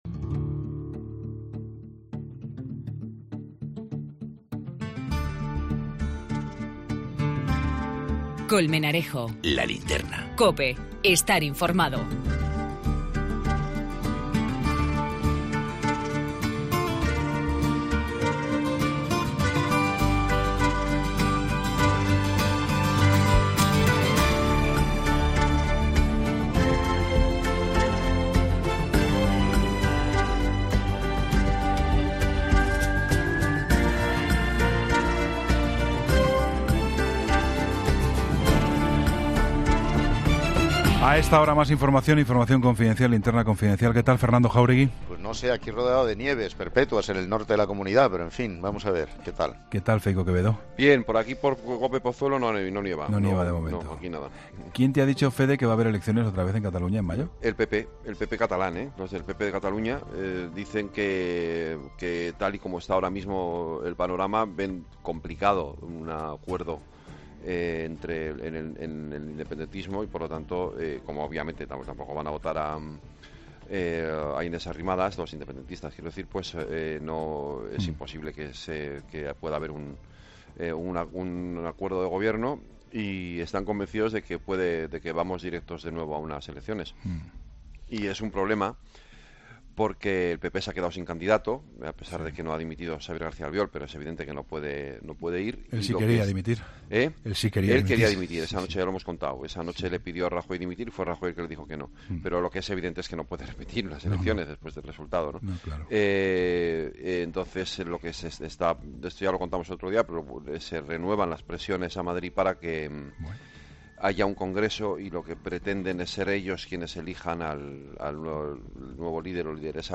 Entrevista con Roberto Bermúdez de Castro, Secretario de Administraciones Públicas.